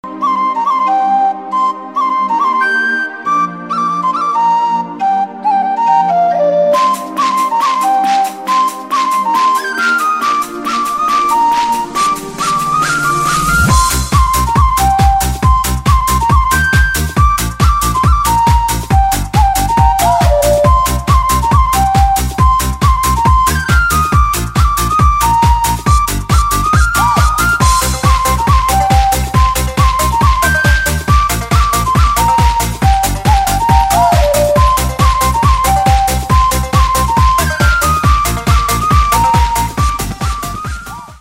• Качество: 192, Stereo
инструментальные
Флейта